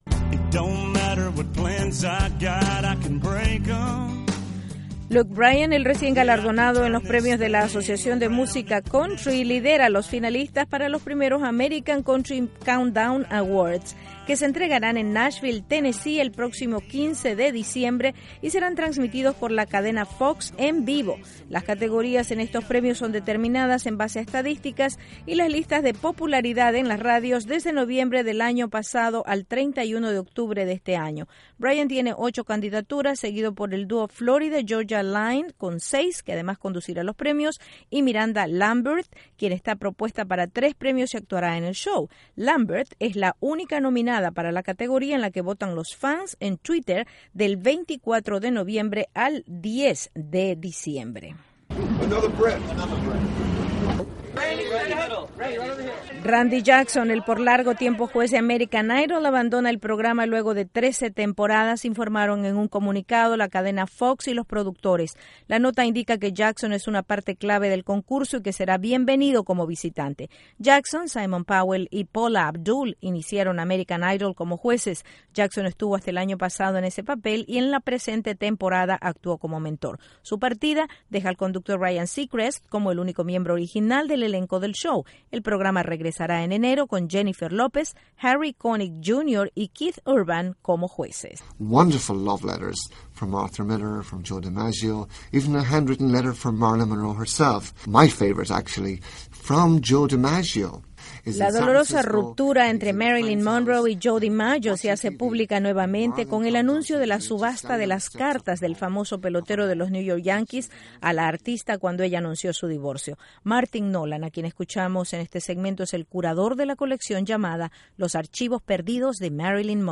Noticias del Entretenimiento grabación correcta